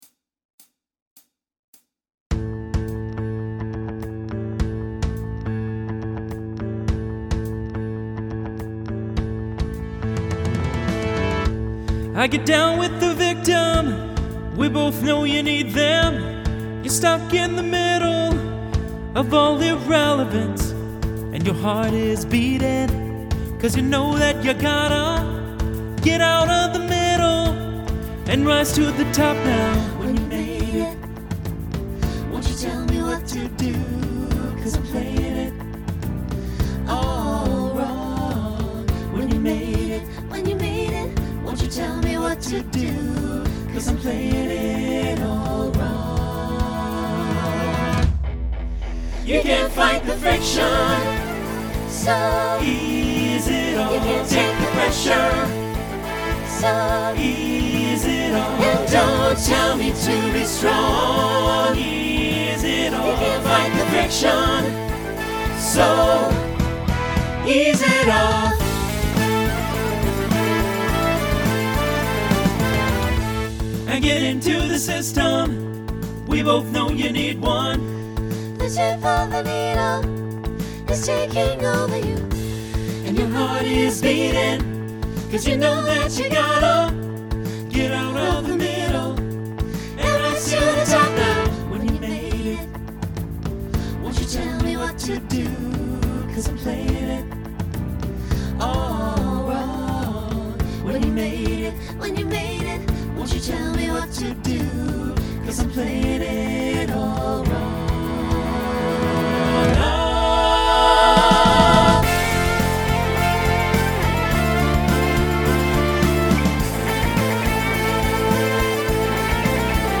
Genre Rock Instrumental combo
Mid-tempo , Opener Voicing SATB